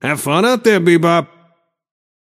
Shopkeeper voice line - Have fun out there, Bebop.